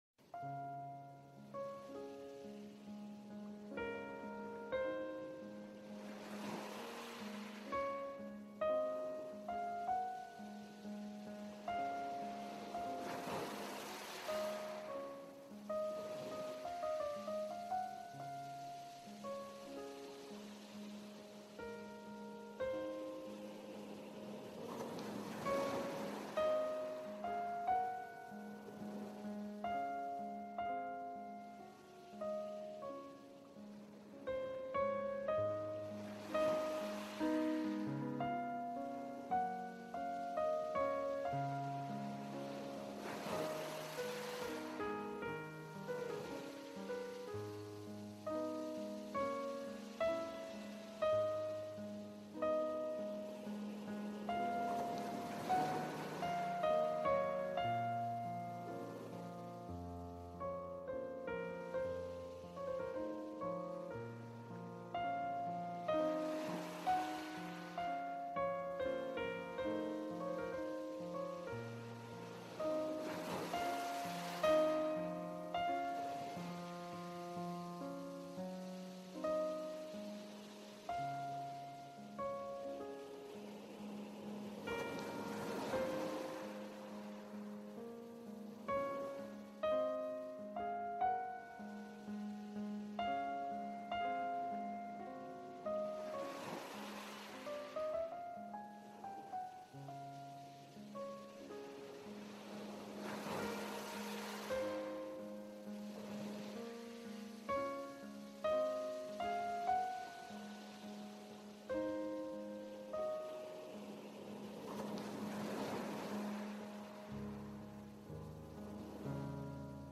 NYC Naturel : Pluie Urbaine Focus